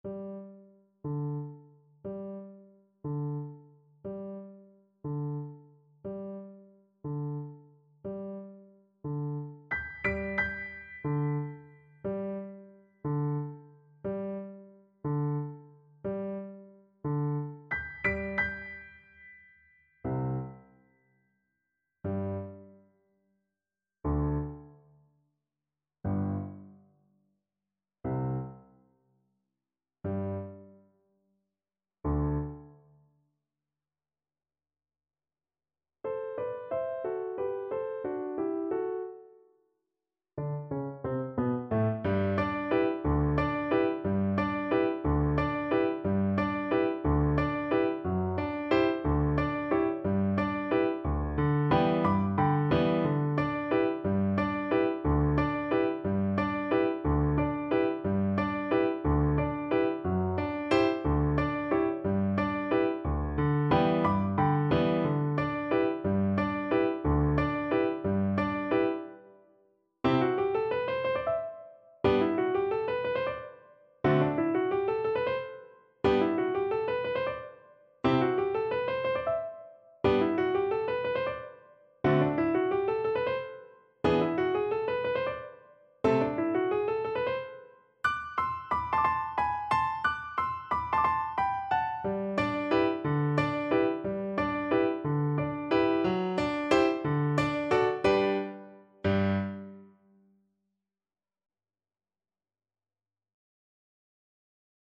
6/8 (View more 6/8 Music)
Classical (View more Classical Viola Music)